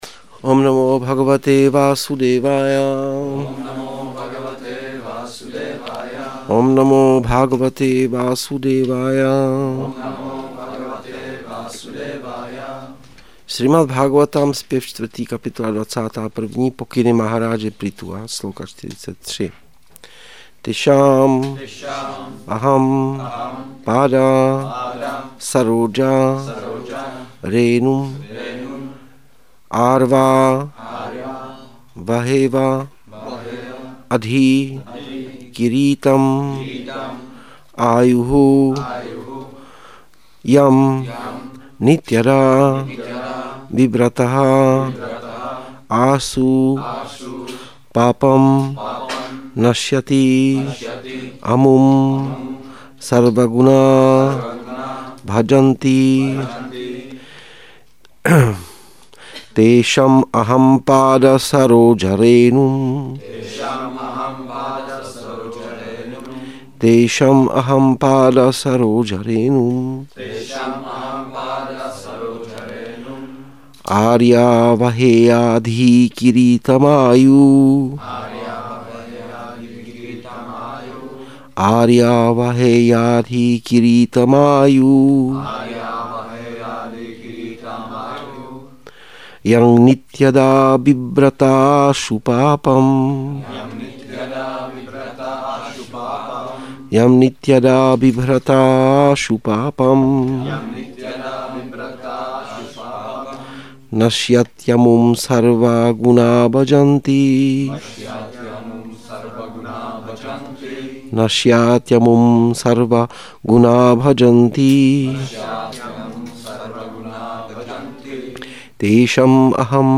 Přednáška SB-4.21.43 – Šrí Šrí Nitái Navadvípačandra mandir